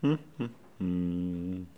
hum-chantonnant_01.wav